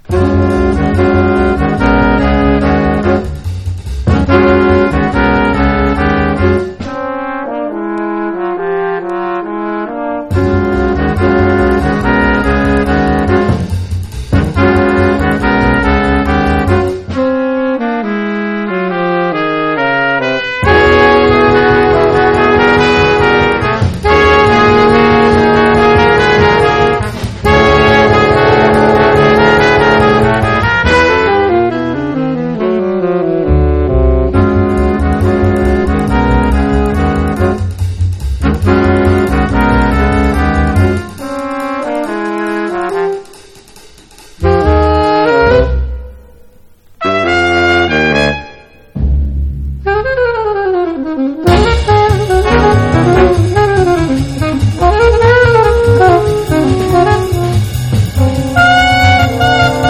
JAZZ / JAPANESE / BIG BAND